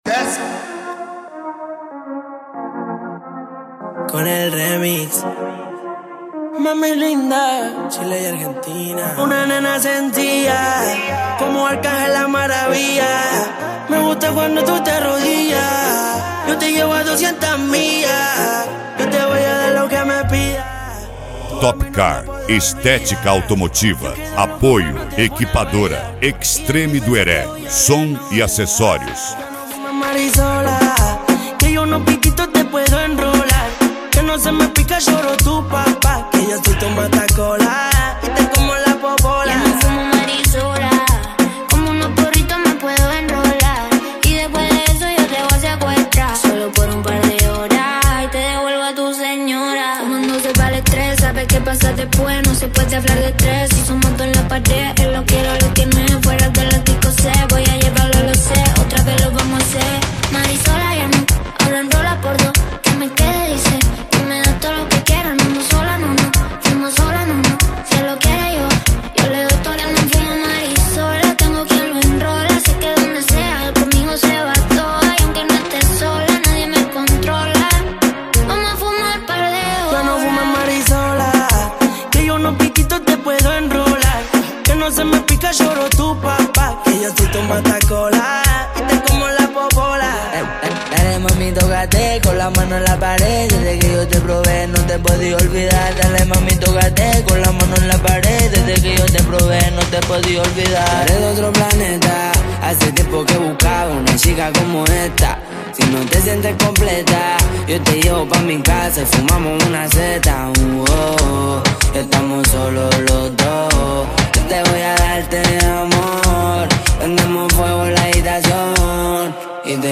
Funk
Mega Funk
Melody
Modao